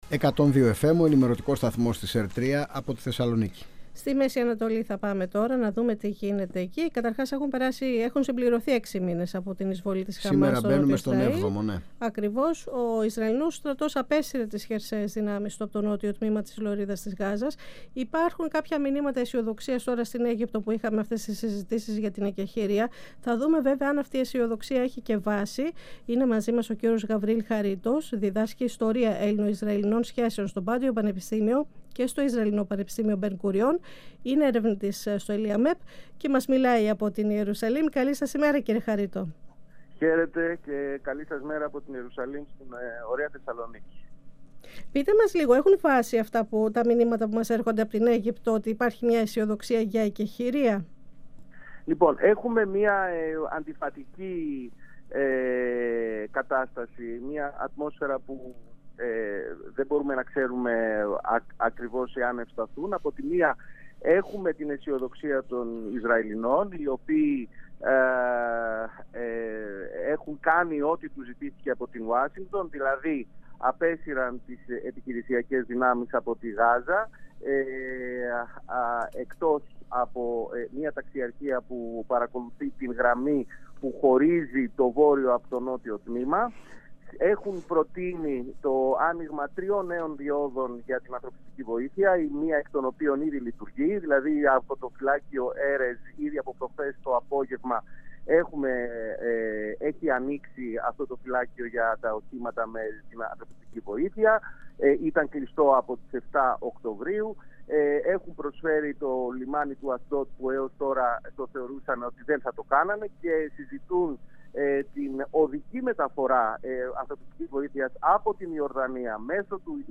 επεσήμανε σε συνέντευξη που παραχώρησε μιλώντας από την Ιερουσαλήμ στην εκπομπή «Αίθουσα Σύνταξης» του 102FM της ΕΡΤ3